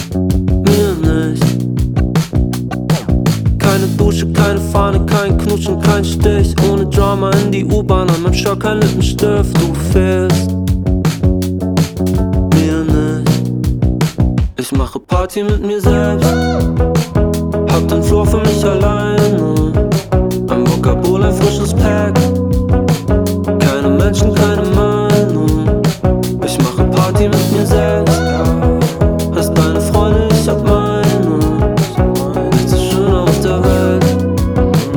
# Инди-поп